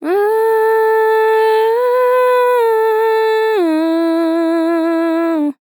High heh Sample
Categories: Vocals Tags: dry, english, female, fill, heh, High, LOFI VIBES, sample